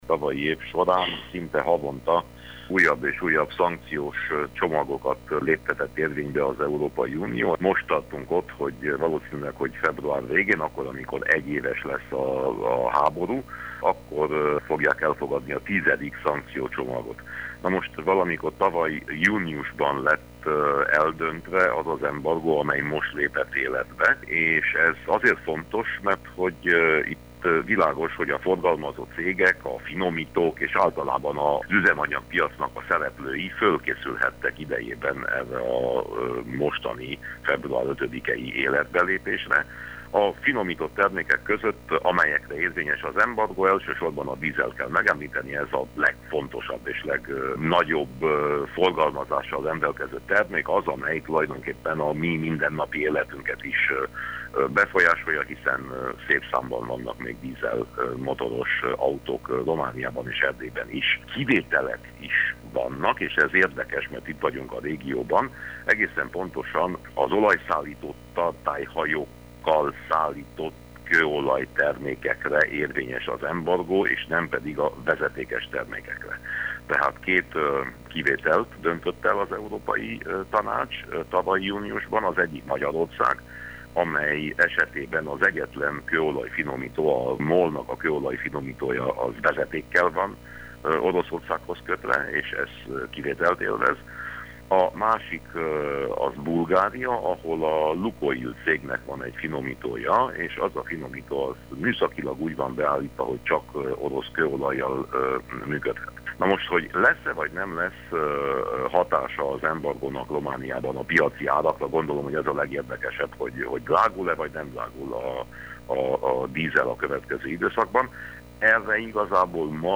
Hogy az üzemanyag piaci árára milyen hatással lesz ez a döntés, az a következő időszakban fog kiderülni. Winkler Gyula EP képviselőt hallják.